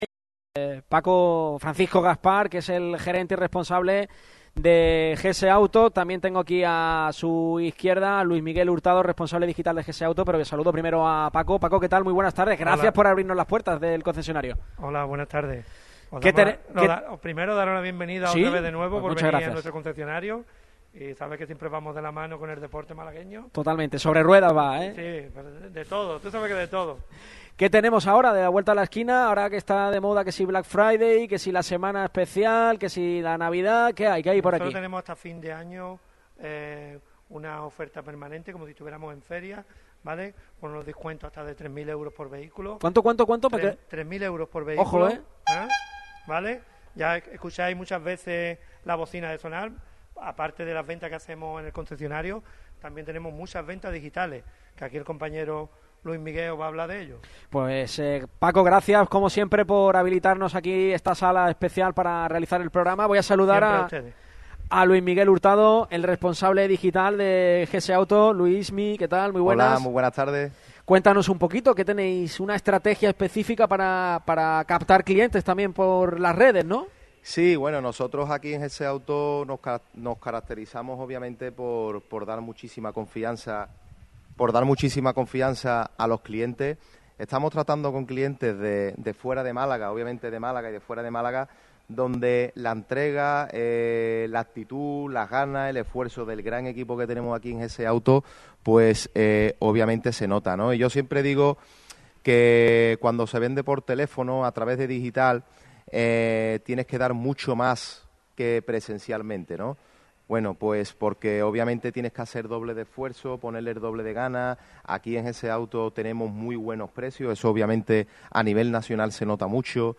Este jueves el equipo de Radio MARCA Málaga ha visitado las instalaciones de GS Autos en el Polígono de Santa Bárbara C/ Hnos Lumiere 17.